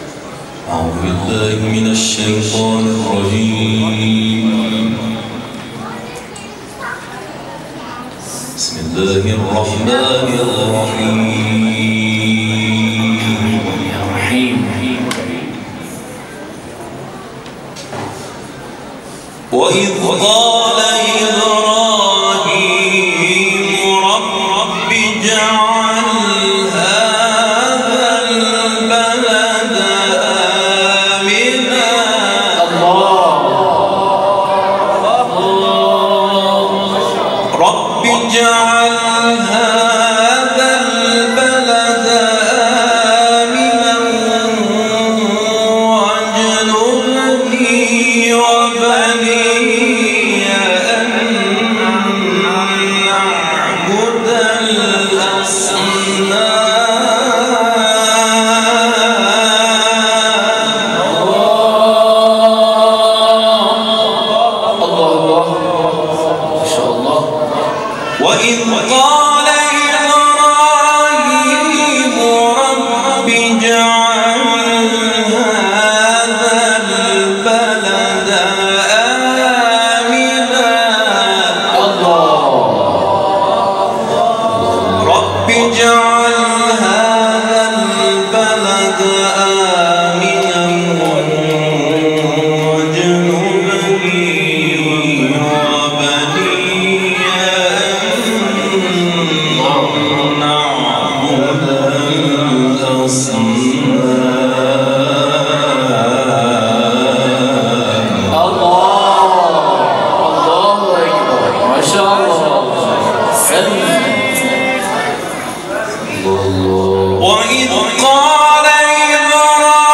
سوره ابراهیم ، تلاوت قرآن